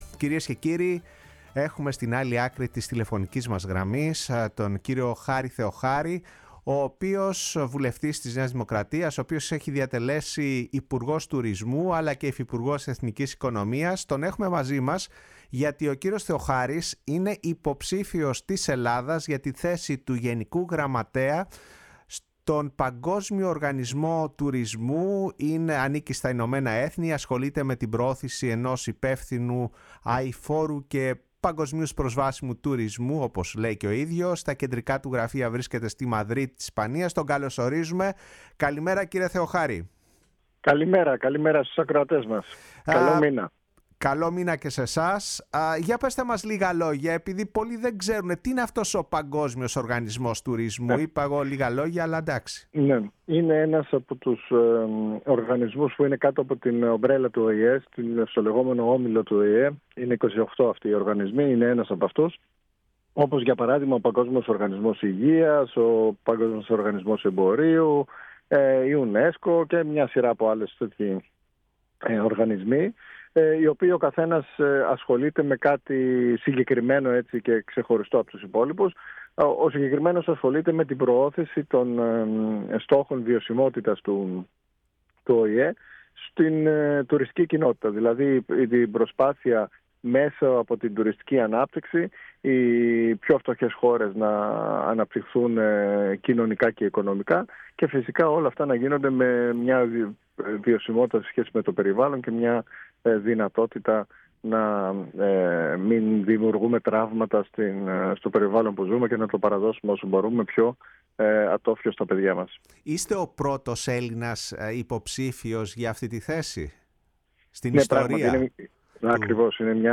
Ο Χάρης Θεοχάρης, βουλευτής της ΝΔ και πρώην υπουργός Τουρισμού, ο οποίος είναι ο πρώτος ‘Ελληνας υποψήφιος για την θέση του Γενικού Γραμματέα του Παγκόσμιου Οργανισμού Τουρισμού, μίλησε για τους λόγους που τον οδήγησαν να θέσει υποψηφιότητα, τον Οργανισμό του ΟΗΕ και την εκλογική διαδικασία, στο ραδιόφωνο της Φωνής της Ελλάδας και στην εκπομπή “Η Παγκόσμια Φωνή μας”